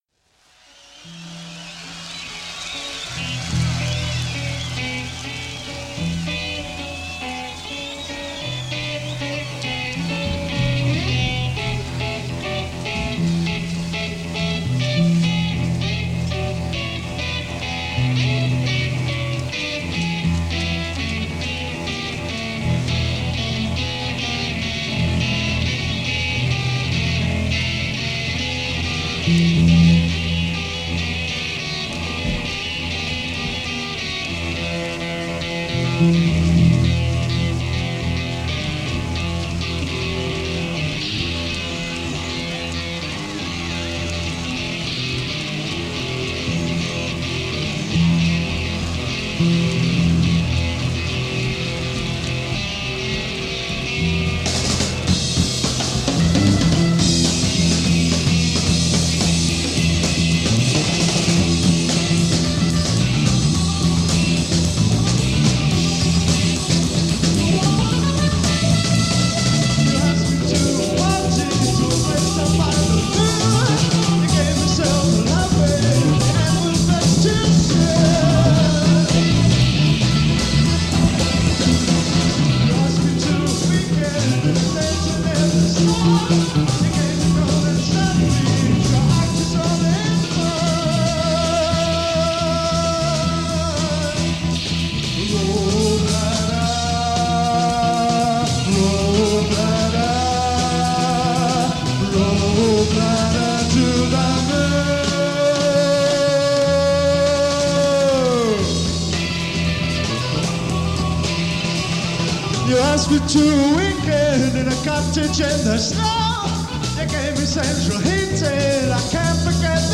live at Ruisrock Festival, Finland